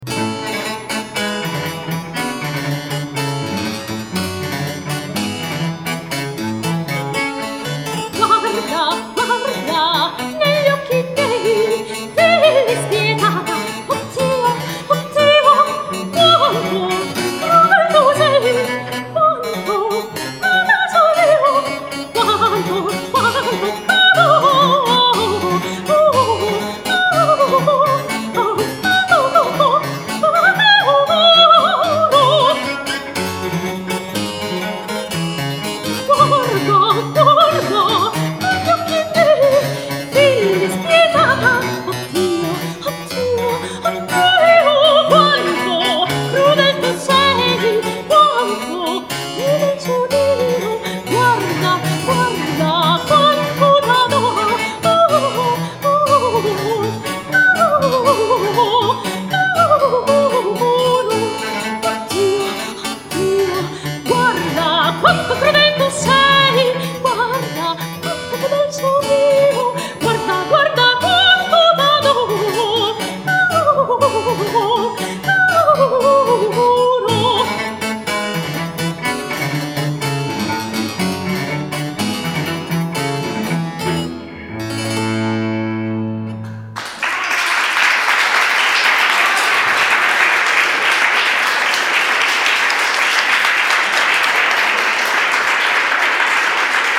"Fonti del pianto" - Cantata per Soprano e Basso Continuo RV 656 di Antonio Vivaldi
Soprano
Violoncello
Clavicembalo